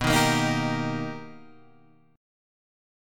B Augmented Major 7th